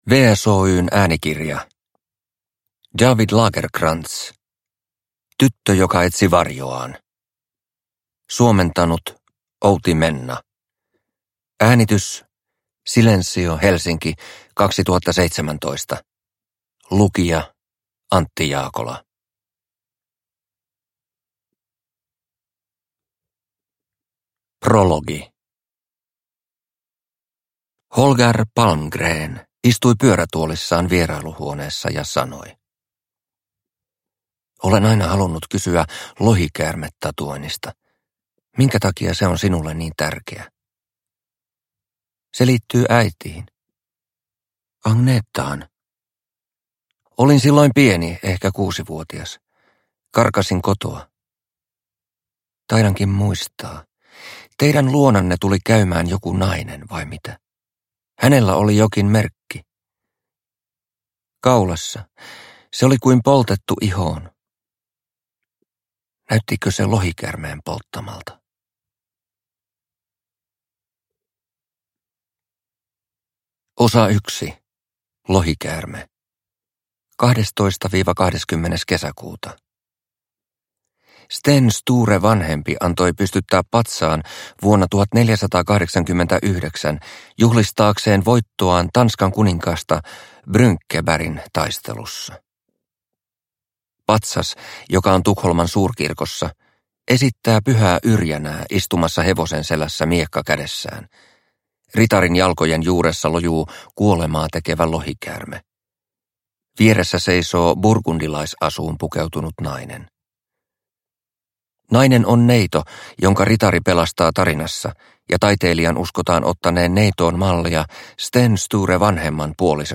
Tyttö joka etsi varjoaan – Ljudbok – Laddas ner